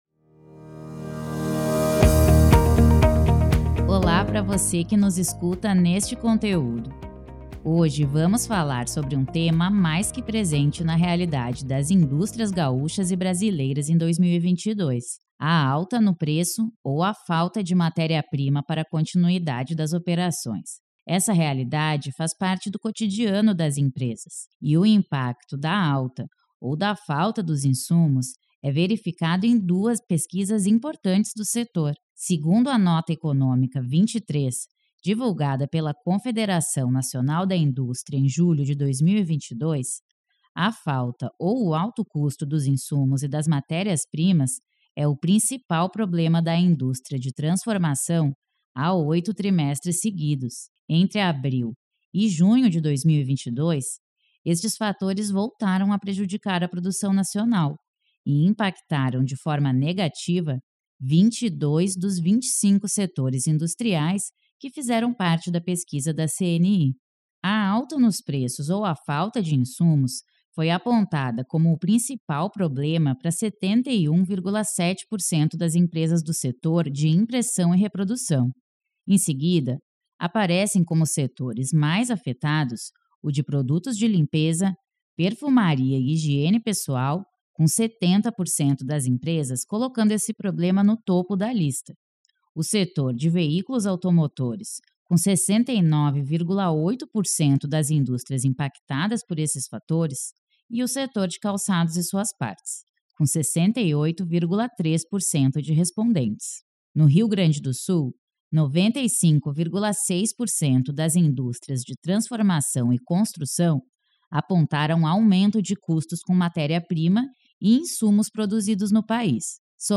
Ouça a entrevista completa com o especialista aqui .